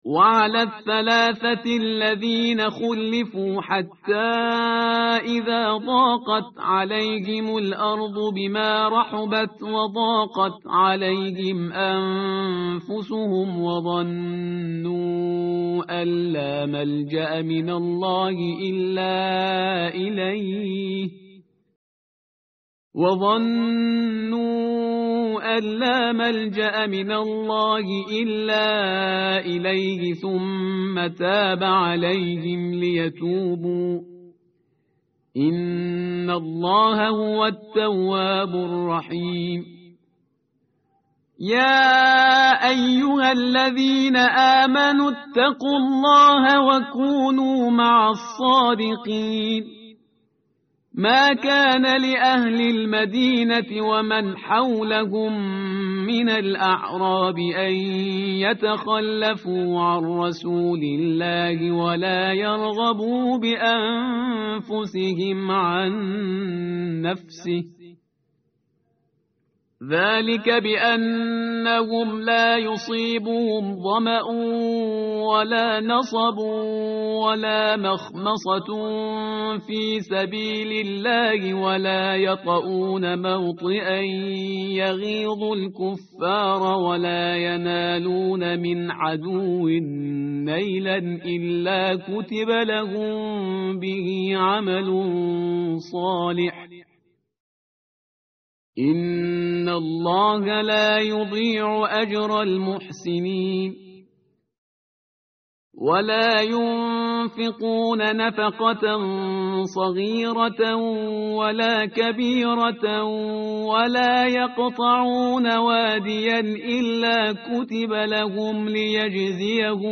tartil_parhizgar_page_206.mp3